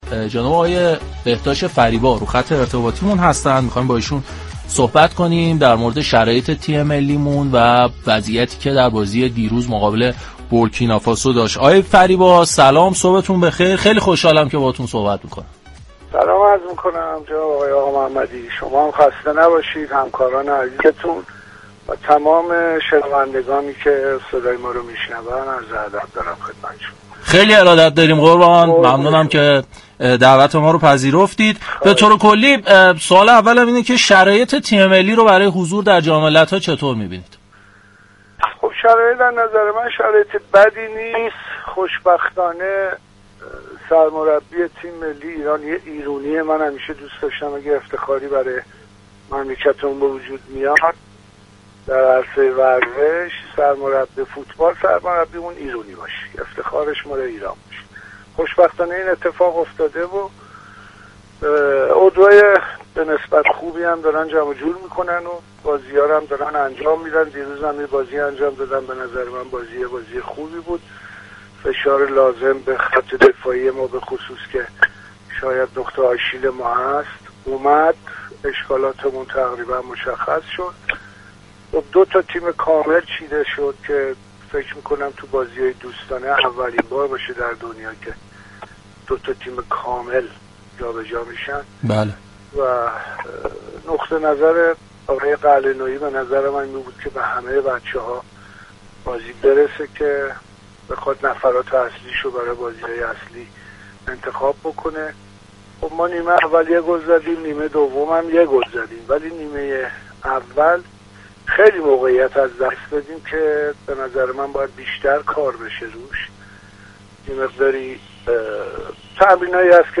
در گفت و گو با «تهران ورزشی»